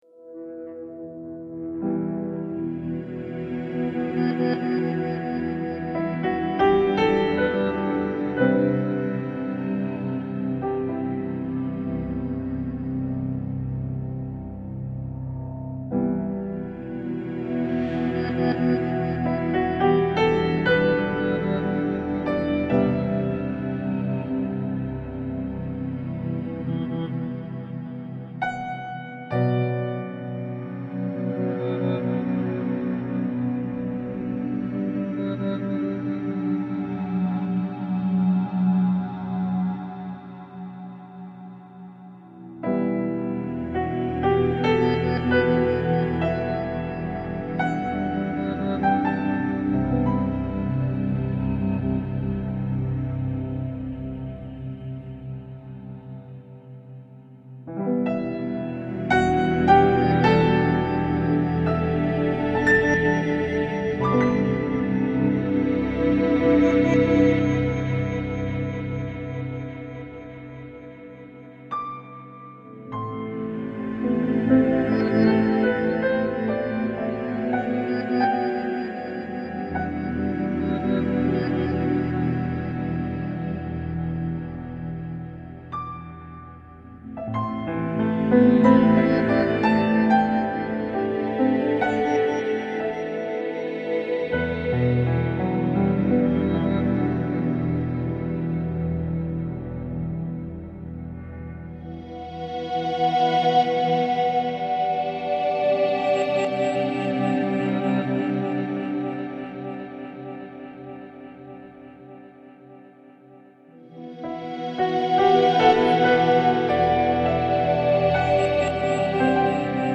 Грустная мелодия о неразделенной любви для фортепиано